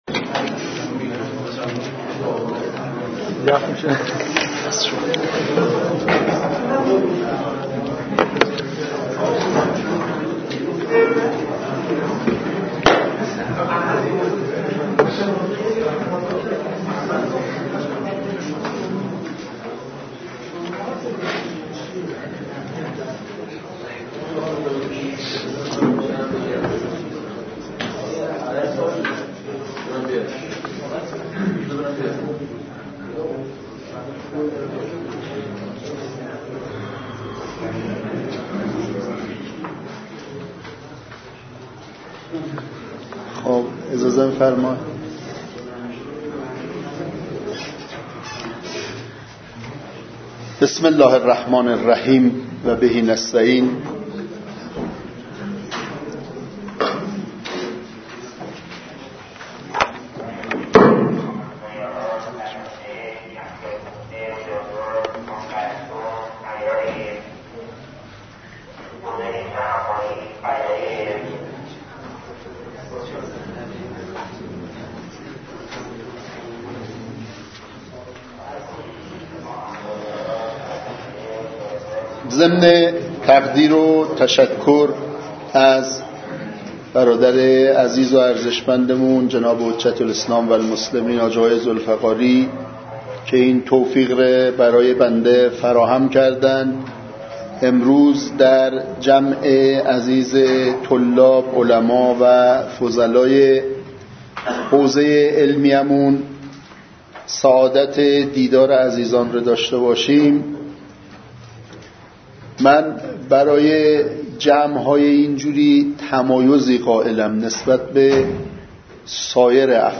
جلسه پرسش و پاسخ با حضور دکتر داود محمدی نماینده مردم قزوین